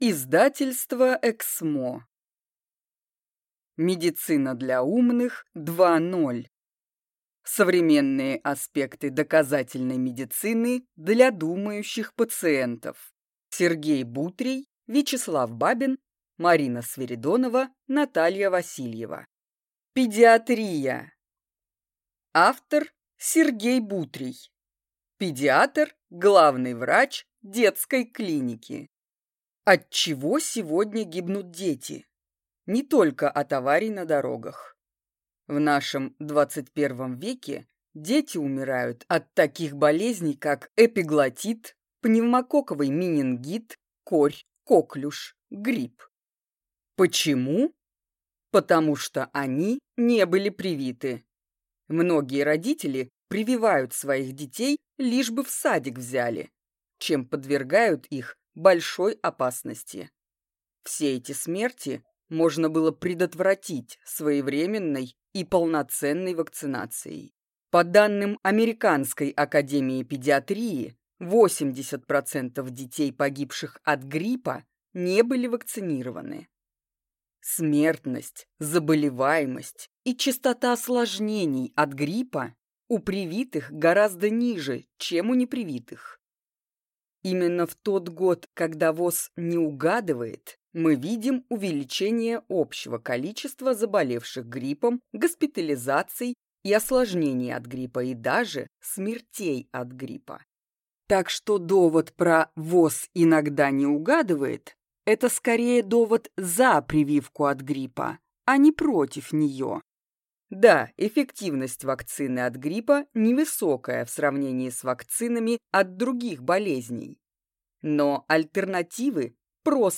Аудиокнига Медицина для умных 2.0. Блок 10: Healthhacking. Дети, путешествия, check up здоровья | Библиотека аудиокниг